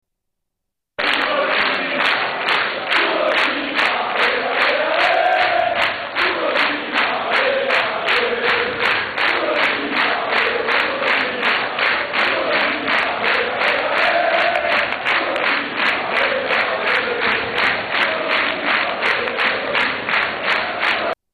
Chant de supporters